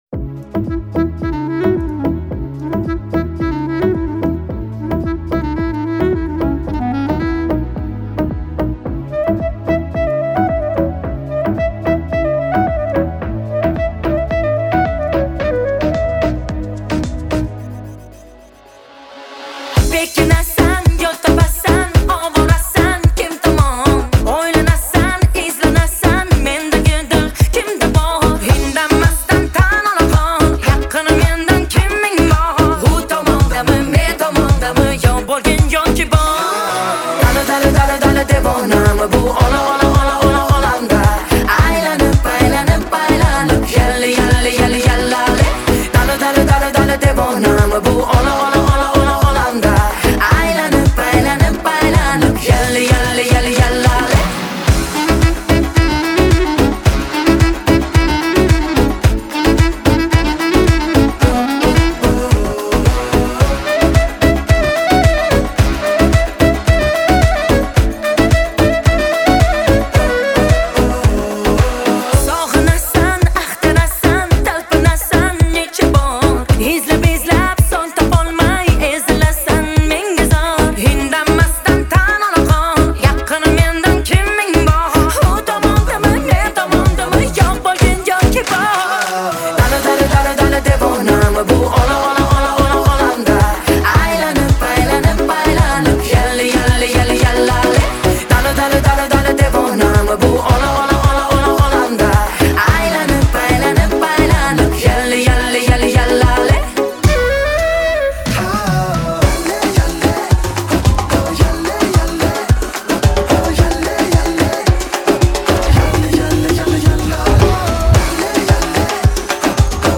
• Жанр: Музыкальные шедевры